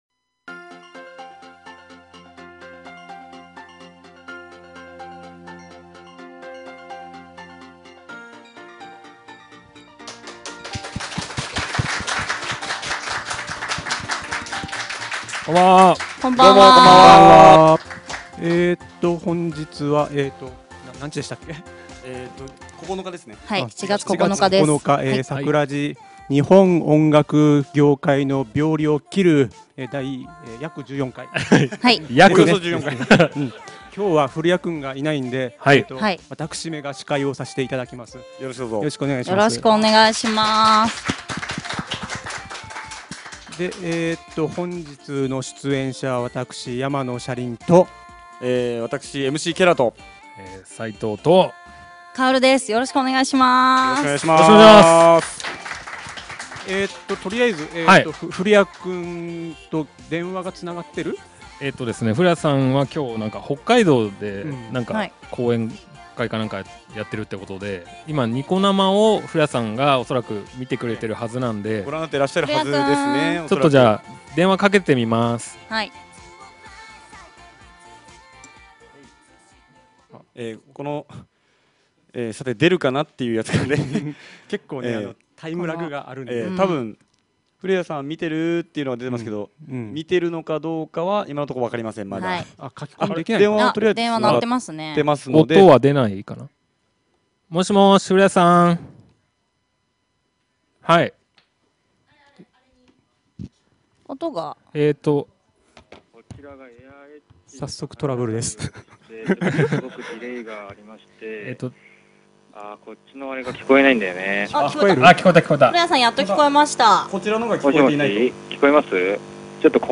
今回はこの４人で熱く語り合いました！